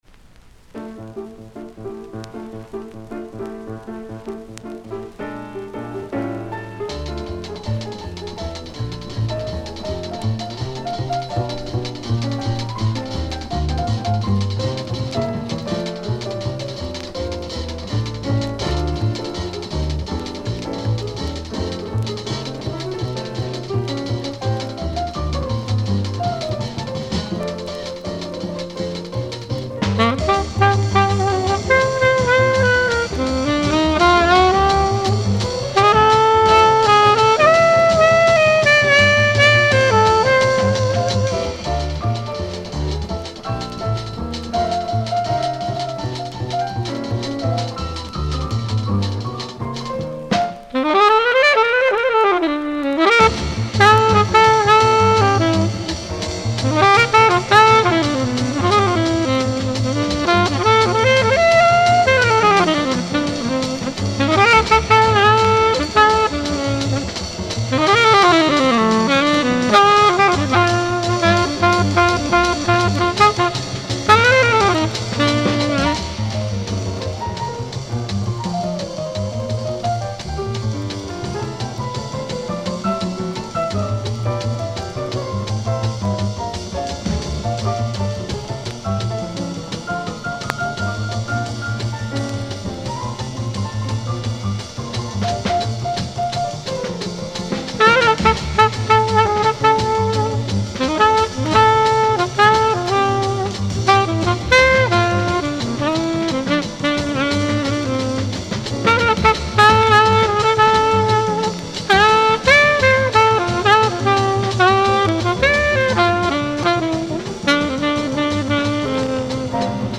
• Saxophone
• Bass
• Drums
• Piano